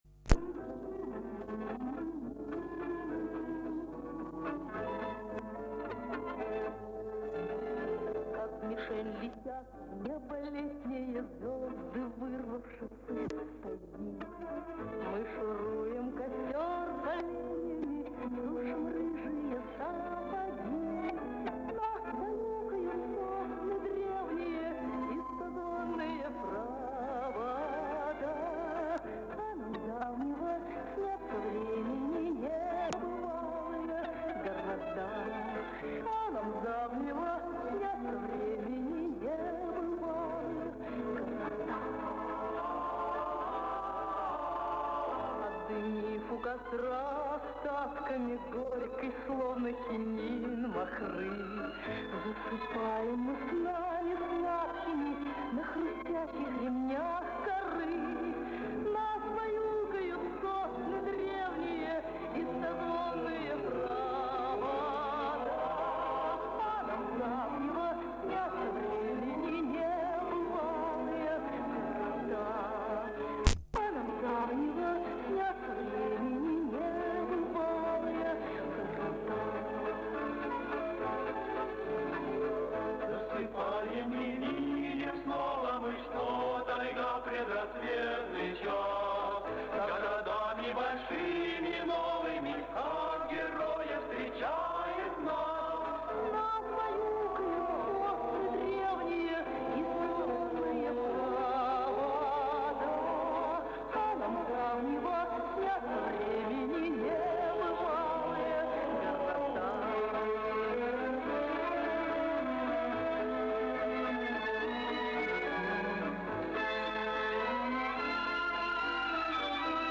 Запись около 1970 года.